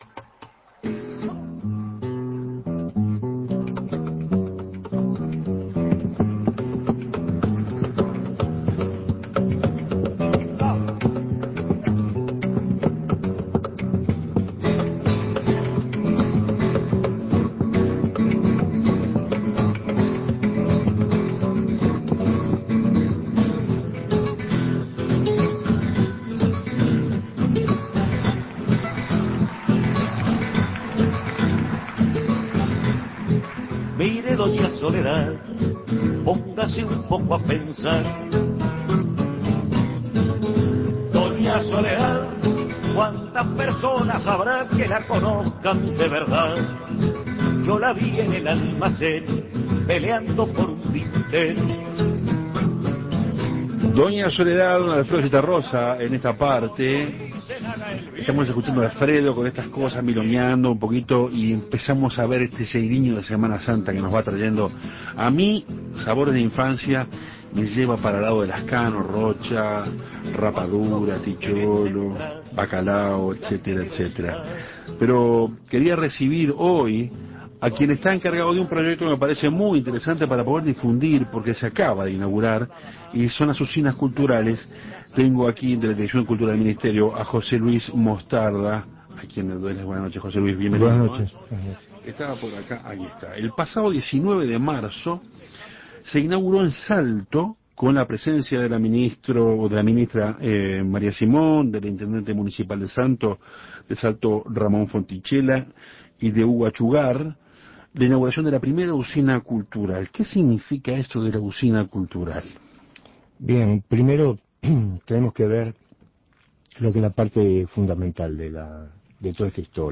fue entrevistado en Café Torrado.